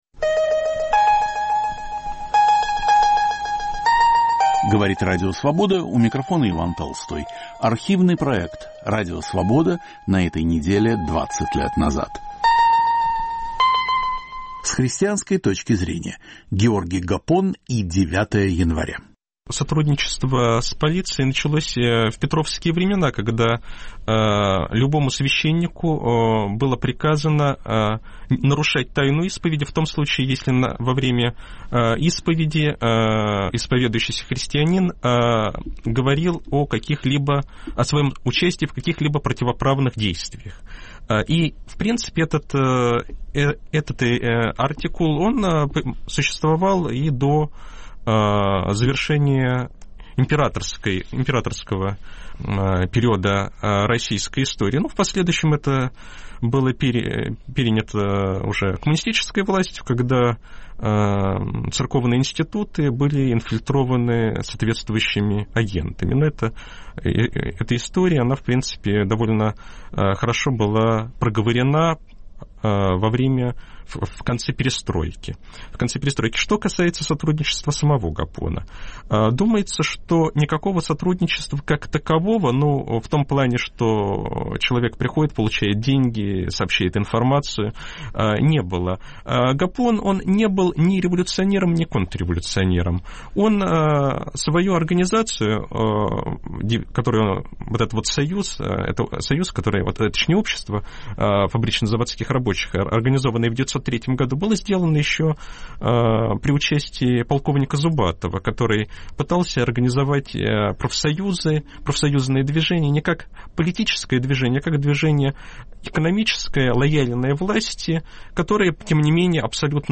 Роль священника Георгия Гапона: были ли он тайным агентом? Насколько была неизбежна трагедия. В студии